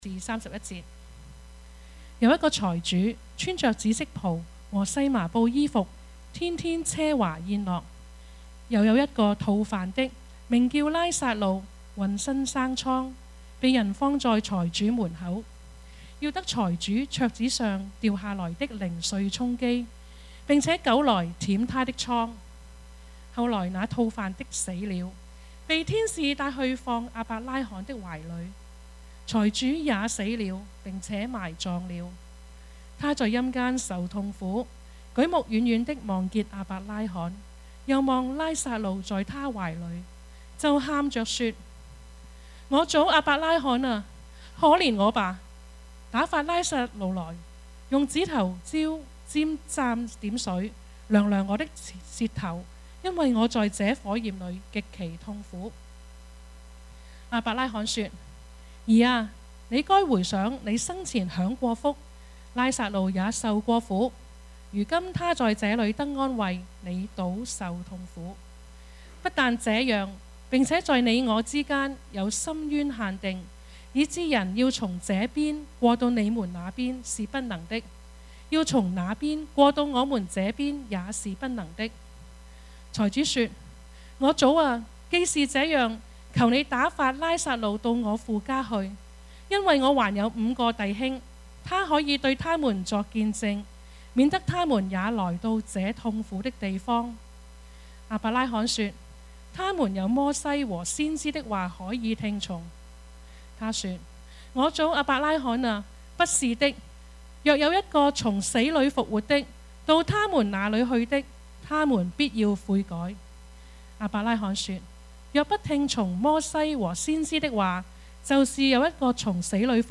Service Type: 主日崇拜
Topics: 主日證道 « 選擇上好的福份 門徒的禱告 »